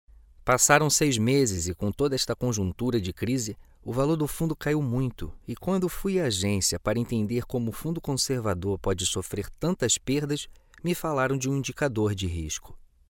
Voces profesionales brasileñas.
locutor Brasil, Brazilian voice over